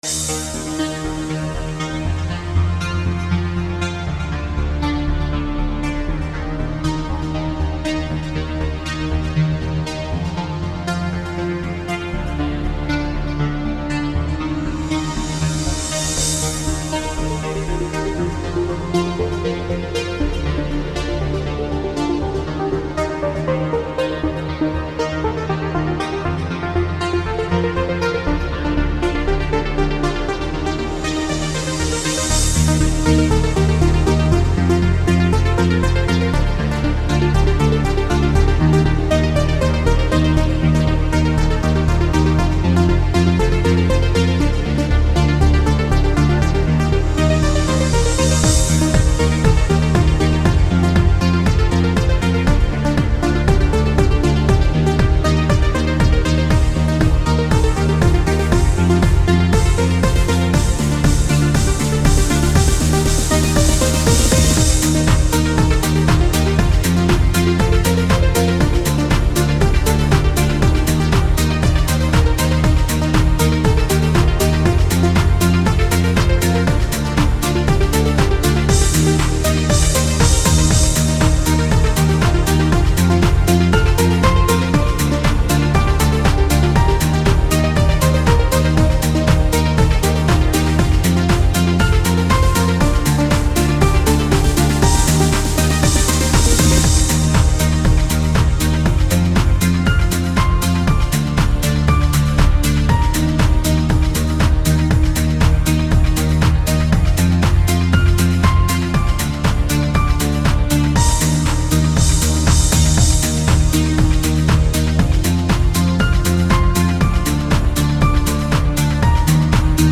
Something slow and easy to listen to with a catchy melody.
Music / Trance